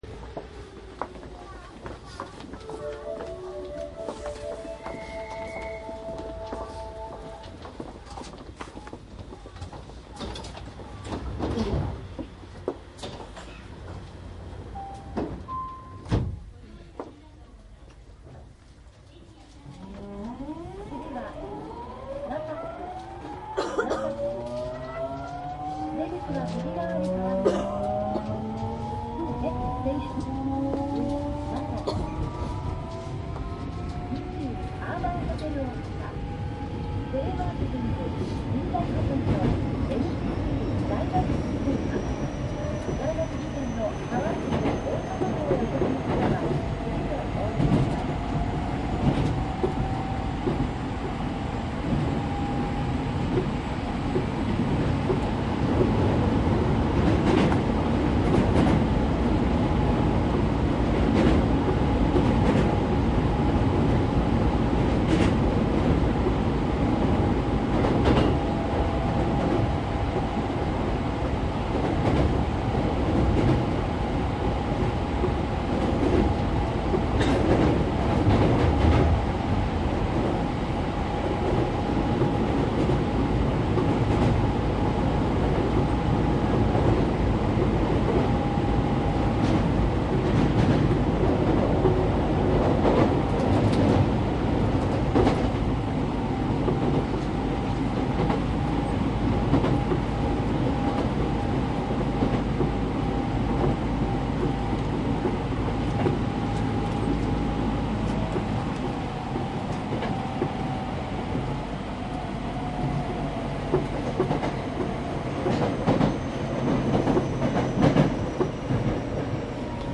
鉄道走行音CD◆北大阪急行８０００系（北大阪急行・大阪市営地下鉄御堂筋線）
動力車での収録でモーター音や車内放送・ドア開閉音を楽しめるほか、乗車気分をご家庭のCDプレーヤーで気軽に楽しめます。音源はDAT使用のデジタル音源で、音質にこだわりました。
なお、収録時における車内の雑音（乗客の声）など極力抑えるようには努めておりますが、完全に防ぐことはできませんのでご了承ください。冬季限定で収録しておりますので空調音は一切ありません。
①８０００系（モハ８１０３） 北大阪急行・大阪市営地下鉄御堂筋線（各停）千里中央→なかもず
注意事項収録機材は、ソニーDATと収録マイクソニーECM959を使用.。